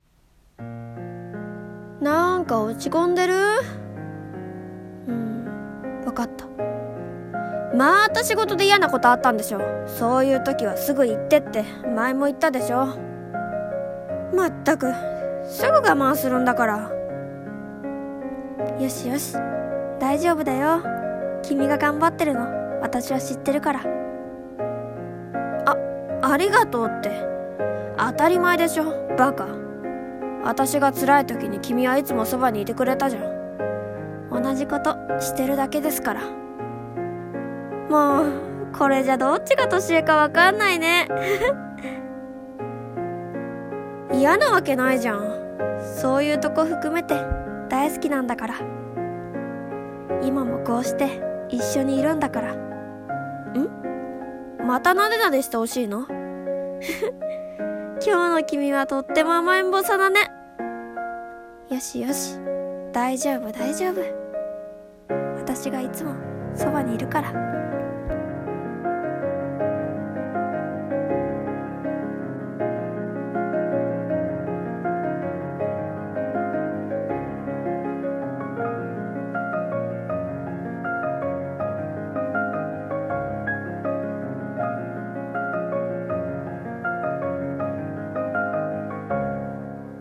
声劇[大丈夫]【１人声劇】